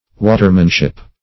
watermanship - definition of watermanship - synonyms, pronunciation, spelling from Free Dictionary
Search Result for " watermanship" : The Collaborative International Dictionary of English v.0.48: Watermanship \Wa"ter*man*ship`\, n. 1.